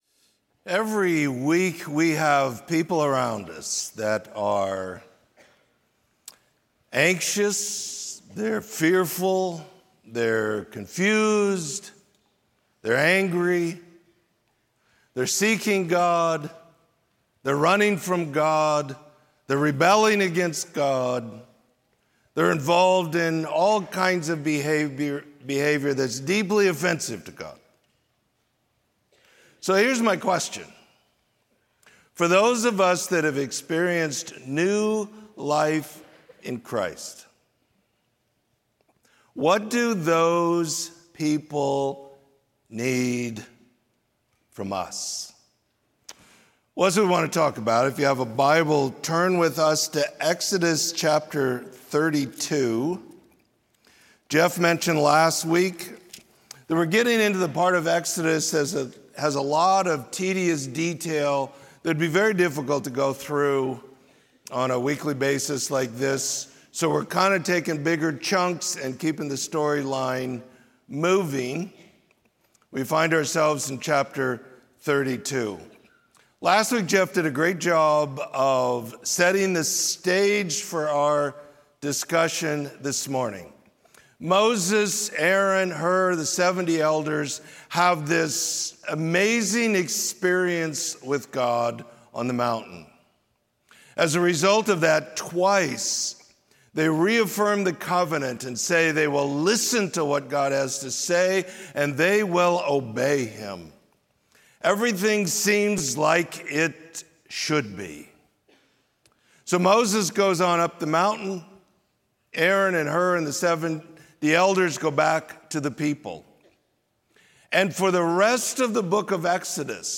Sermon: Favor With God